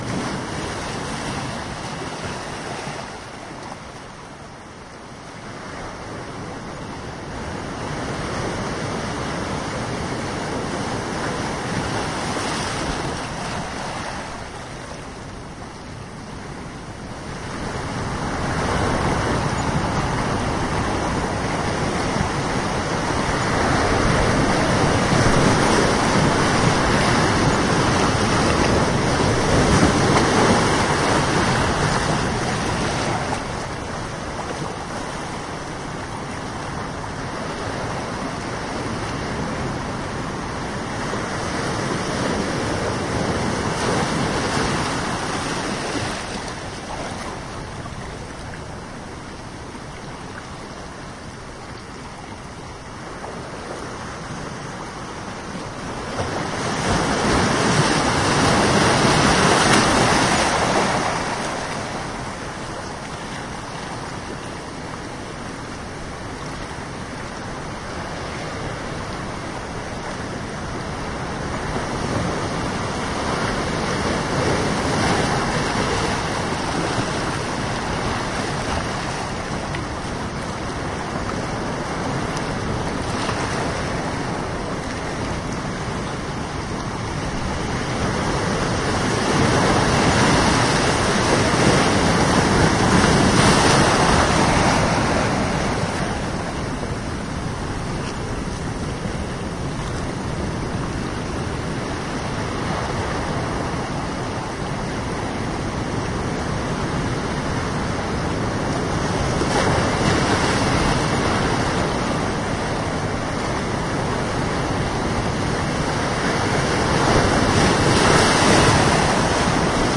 海洋 " 海浪3
描述：雷耶斯角的海浪。被编辑成一个循环。使用索尼MZRH1迷你碟录音机和未经修改的松下WM61驻极体电容式话筒胶囊。
标签： 点雷耶斯 沙滩 海浪 环路 海边 飞溅 海洋 现场录音 晃动 立体声
声道立体声